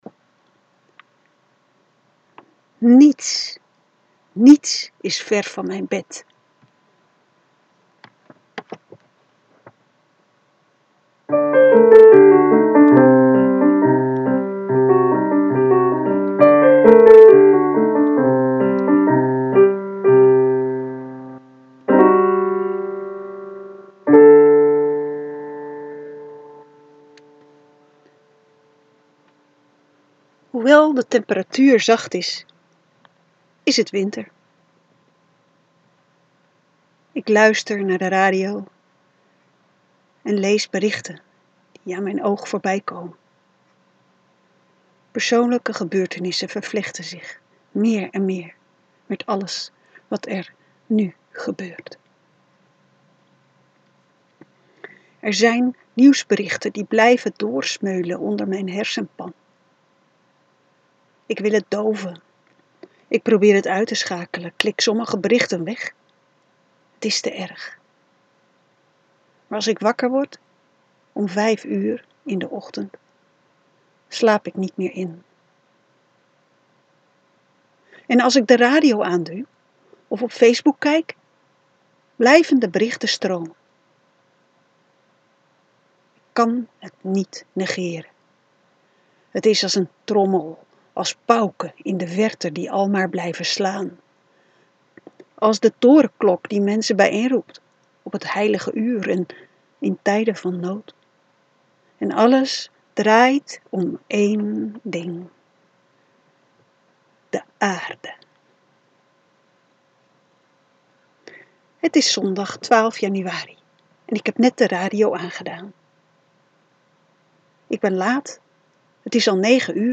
(Ga naar het voorgelezen verhaal door te klikken op de audiobalk onderaan.)